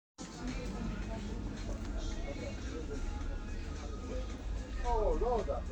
Piosenka z Sklepu Dino 09.05.2025